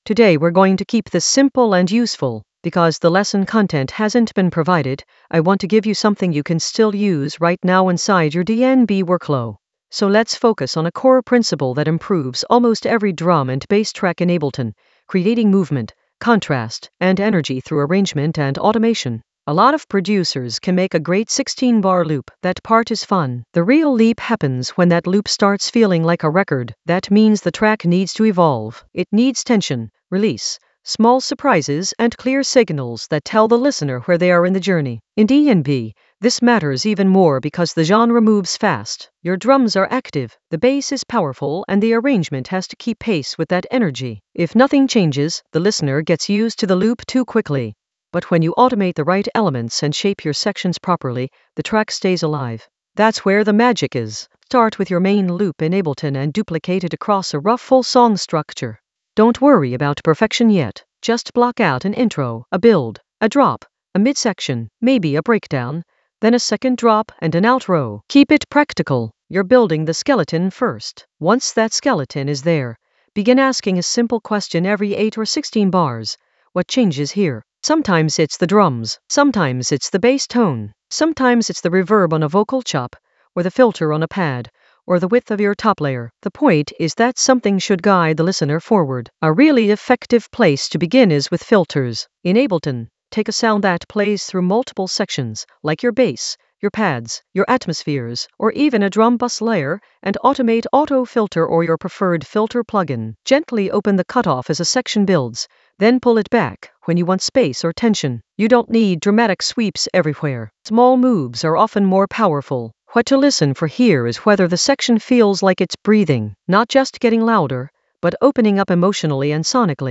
An AI-generated beginner Ableton lesson focused on L Double sub basslines that rattle in the Basslines area of drum and bass production.
Narrated lesson audio
The voice track includes the tutorial plus extra teacher commentary.